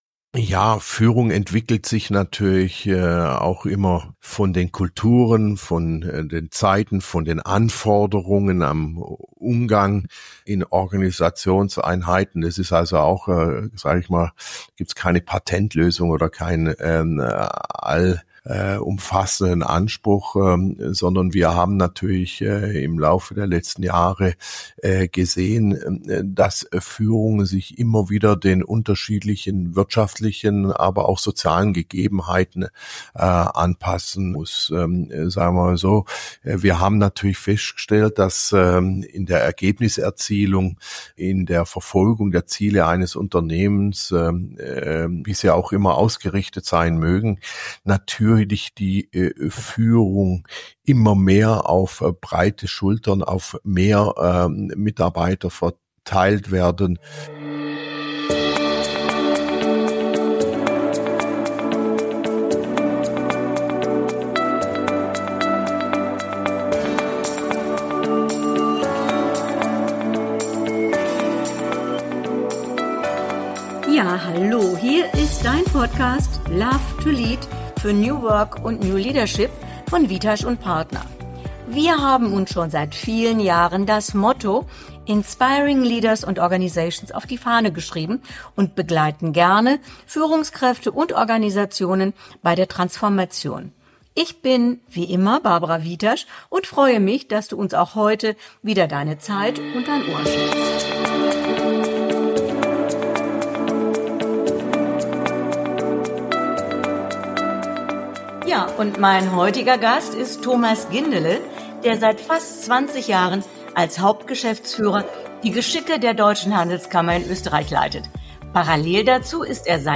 Auf dich wartet ein spannendes und inspirierendes Interview.